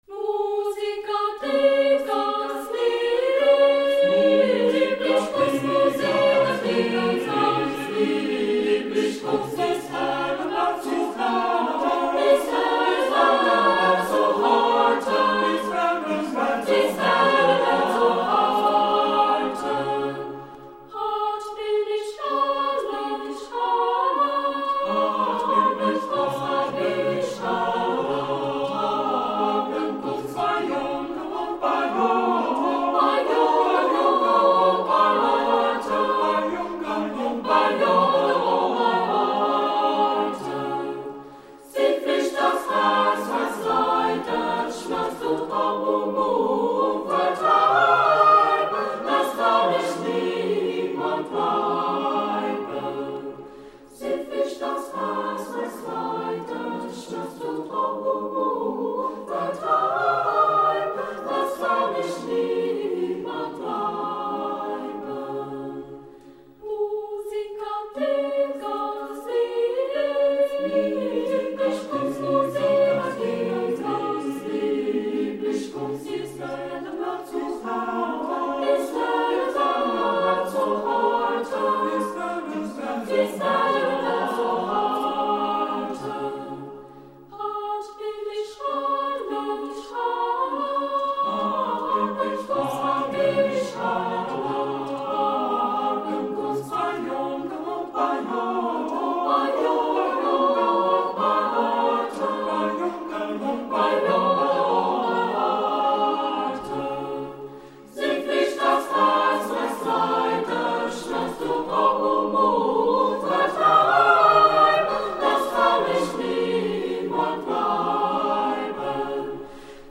录音地址:石碑胡同中国唱片社录音棚
【欧洲牧歌部分】